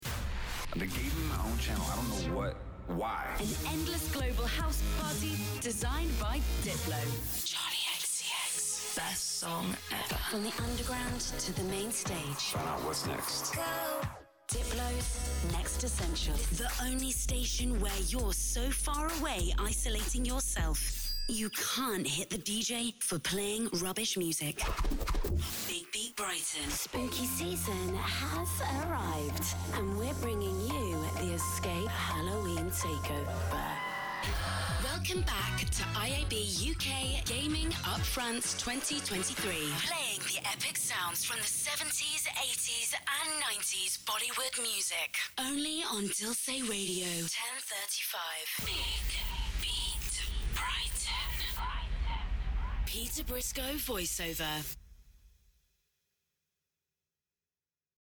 Female
British English (Native)
Approachable, Assured, Authoritative, Bright, Character, Confident, Conversational, Cool, Corporate, Deep, Energetic, Engaging, Friendly, Gravitas, Natural, Posh, Reassuring, Smooth, Upbeat, Warm, Young, Cheeky, Sarcastic, Witty
2025 Imaging Reel Short .mp3
Microphone: Rode NT2-A, Shure SM7B, Shure SM58